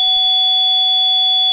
Sirena Multitonal 8 Sonidos
102dB